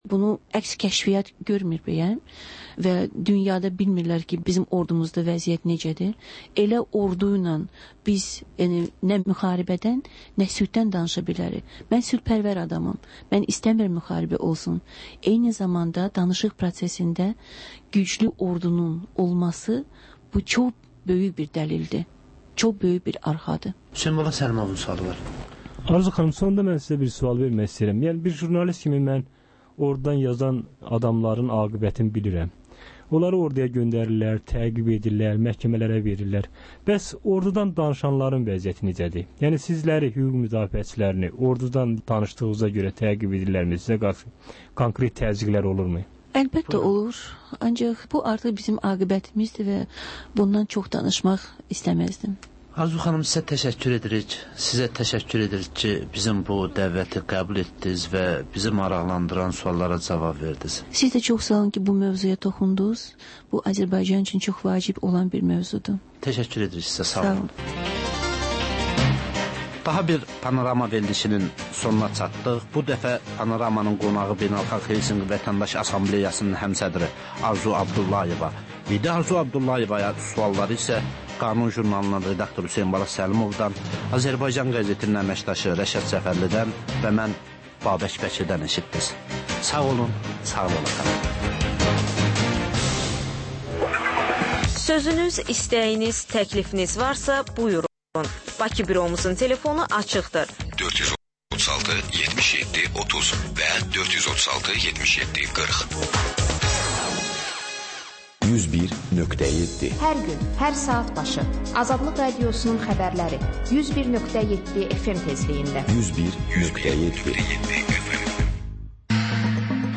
Jurnalistlər və həftənin xəbər adamıyla aktual mövzunun müzakirəsi